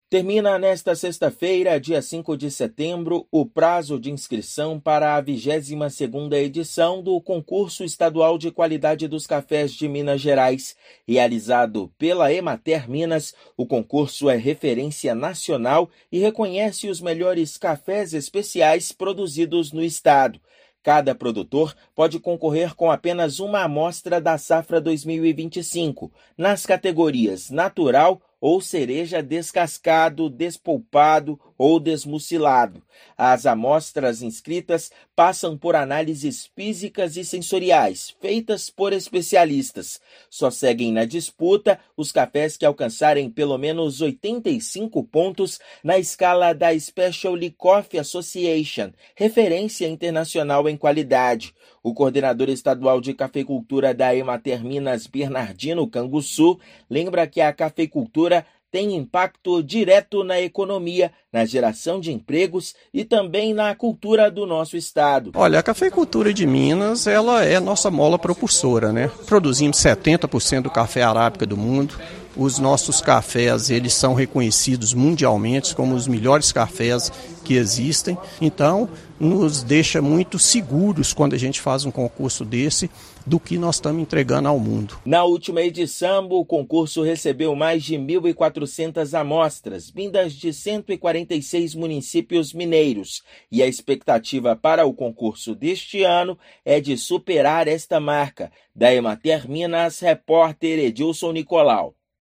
Prazo termina no dia 5/9. Competição revela os melhores cafés especiais do estado. Ouça matéria de rádio.